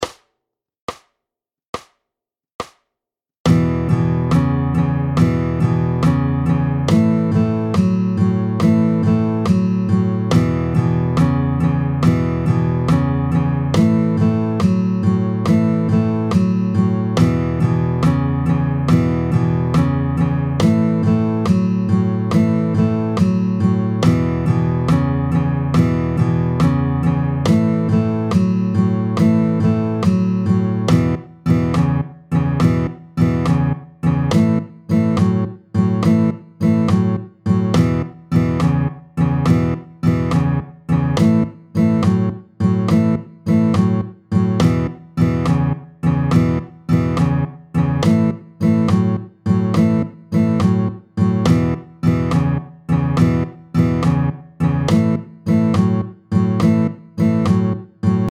32-11 Le honky tonk 1, variante ternaire, tempo 80
Ce gimmick du honky tonk avec cette position de la main gauche vient plutôt du rock (et de la guitare électrique).
La sélection des cordes jouées s’effectue exclusivement à la main droite ; toute la qualité du jeu vient de la précision et de la force des coups de médiators, tous donnés vers le bas pour augmenter la puissance requise par cette rythmique.